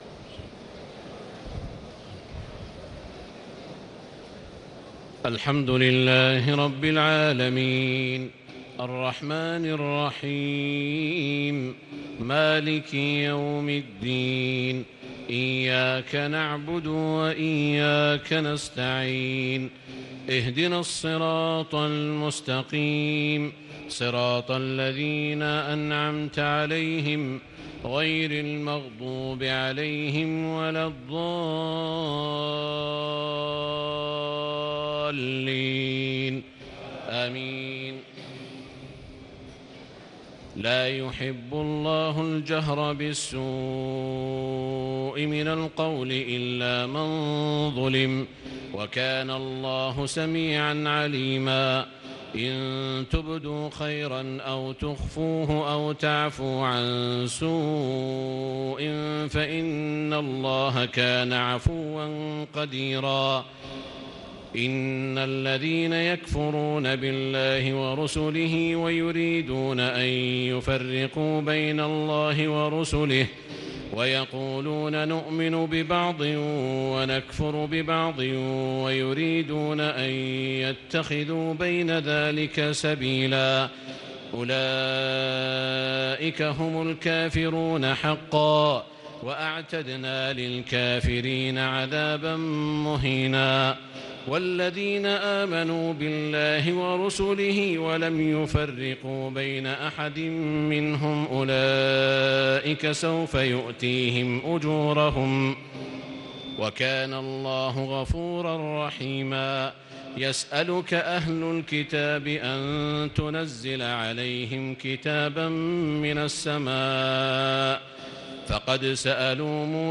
تهجد ليلة 26 رمضان 1438هـ من سورتي النساء (148-176) و المائدة (1-40) Tahajjud 26 st night Ramadan 1438H from Surah An-Nisaa and AlMa'idah > تراويح الحرم المكي عام 1438 🕋 > التراويح - تلاوات الحرمين